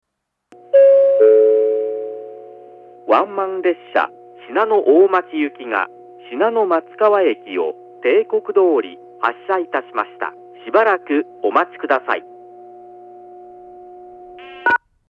この駅の放送はどちらのホームに入線する場合でも上り列車は１番線のスピーカー、下り列車は２番線のスピーカーから放送が流れます。
１番線下り信濃松川駅発車案内放送 ワンマン信濃大町行の放送です。